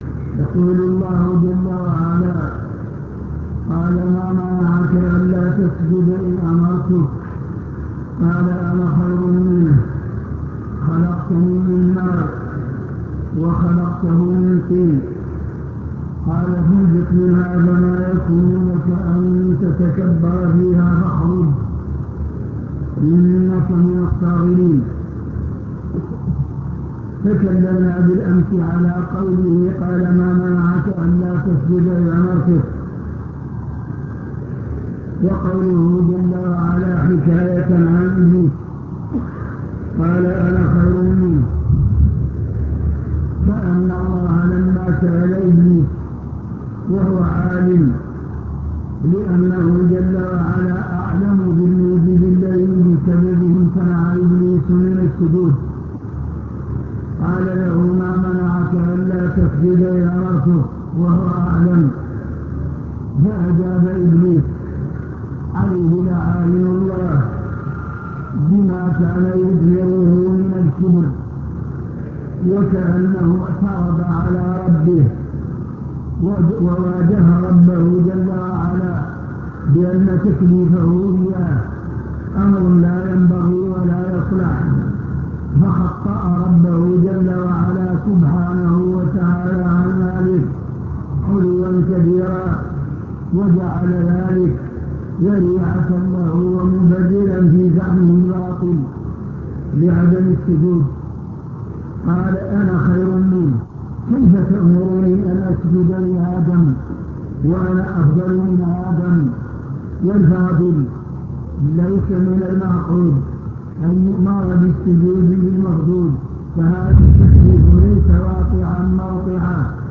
المكتبة الصوتية  تسجيلات - محاضرات ودروس  الرد على ابن حزم